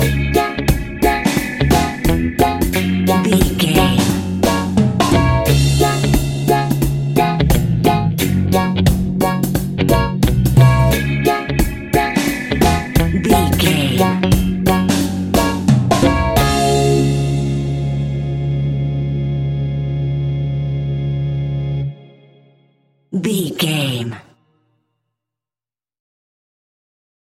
A groovy and funky piece of classic reggae music.
Aeolian/Minor
D♭
instrumentals
laid back
chilled
off beat
drums
skank guitar
hammond organ
percussion
horns